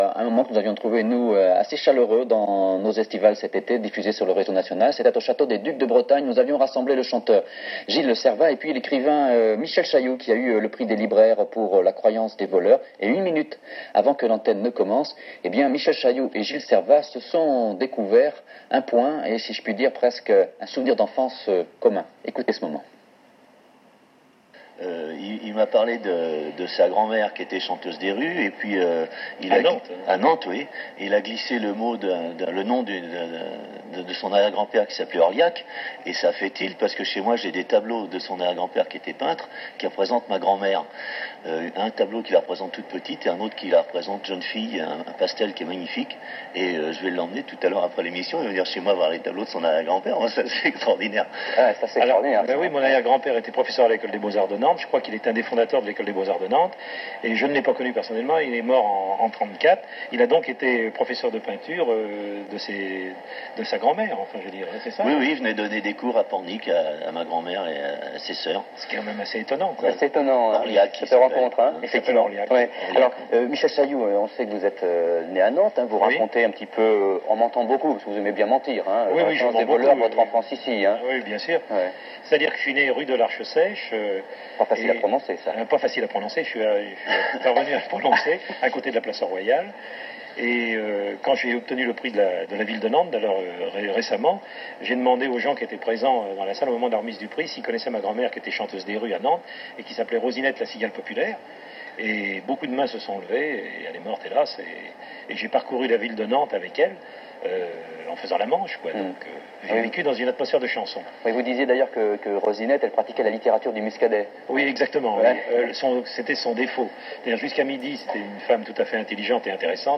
Avec-Gilles-Servat-TV-1989.m4a